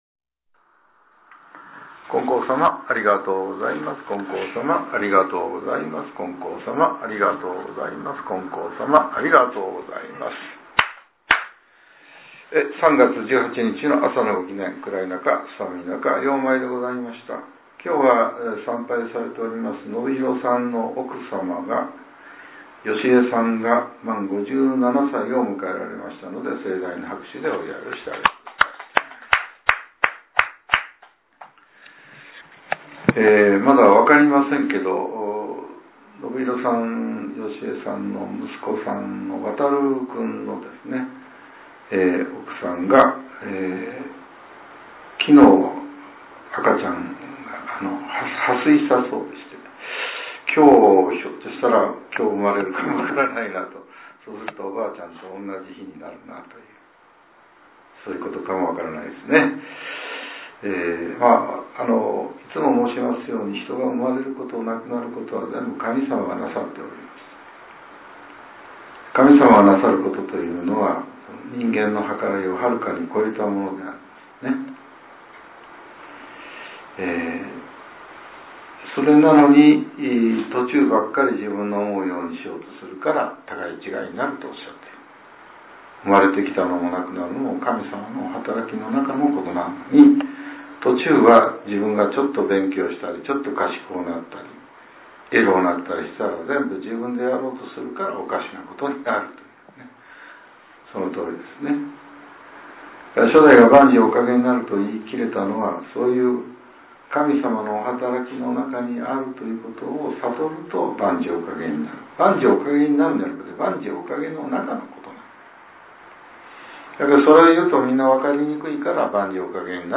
令和８年３月１８日（朝）のお話が、音声ブログとして更新させれています。